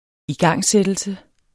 Udtale [ iˈgɑŋˀˌsεdəlsə ]